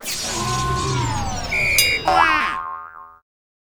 phasefail.wav